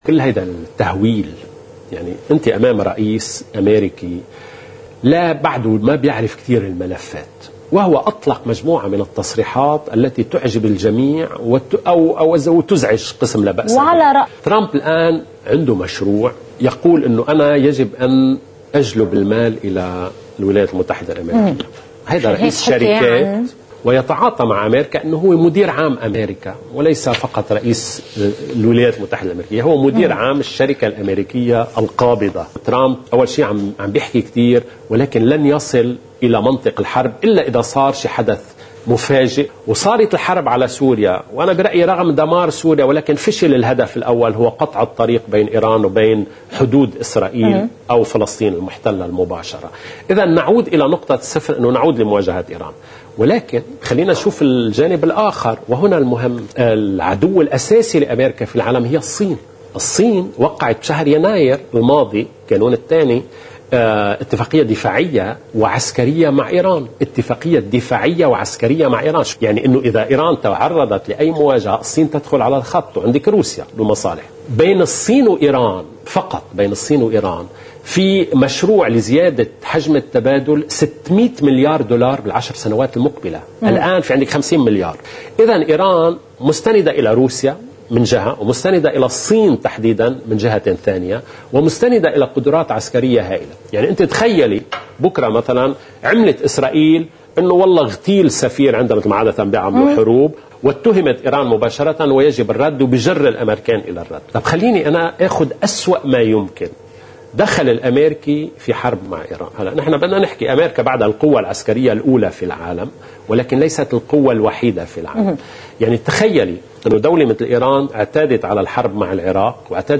مقتطف من حديث المحلل السياسي